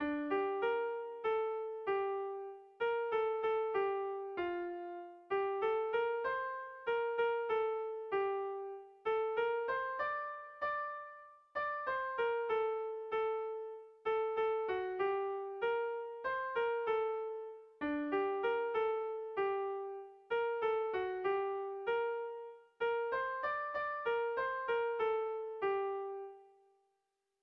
Air de bertsos - Voir fiche   Pour savoir plus sur cette section
Kontakizunezkoa
Seiko handia (hg) / Hiru puntuko handia (ip)
ABD